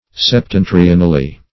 \Sep*ten"tri*on*al*ly\